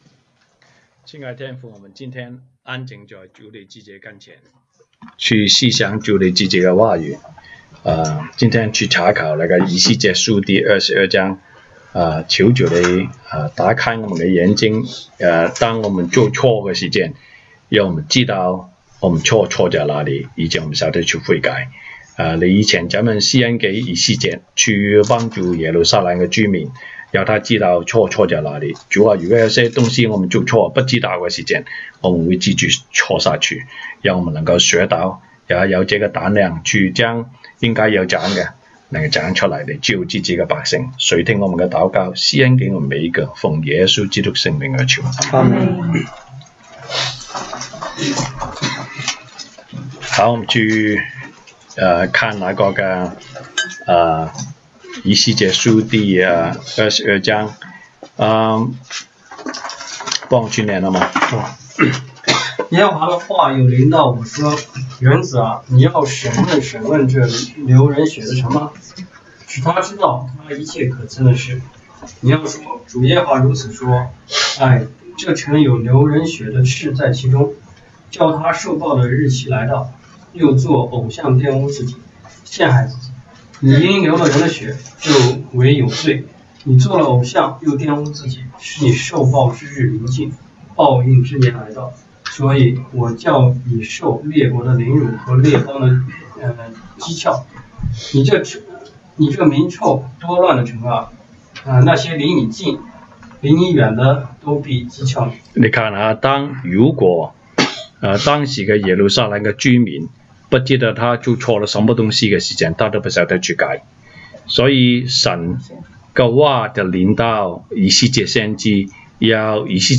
Monday Bible Study